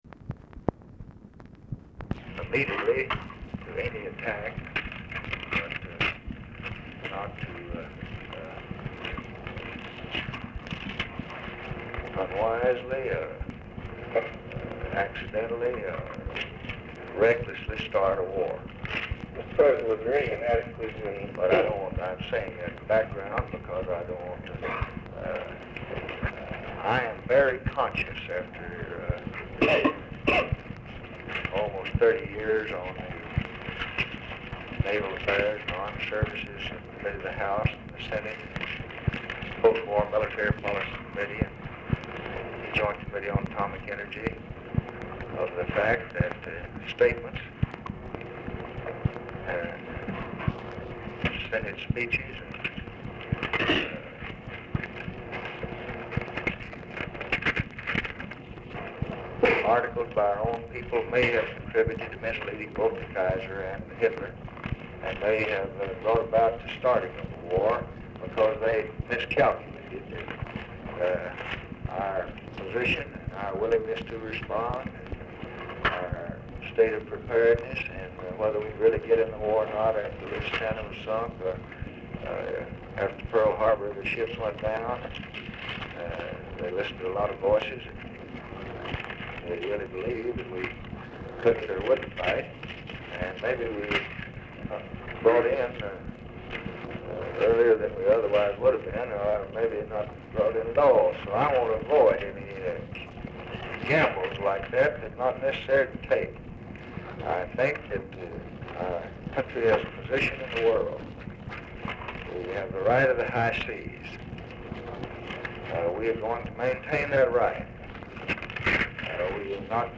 Telephone conversation # 5703, sound recording, PRESS CONFERENCE (with LBJ), 9/19/1964, 1:10PM
POOR SOUND QUALITY
Format Dictation belt
Location Of Speaker 1 Oval Office or unknown location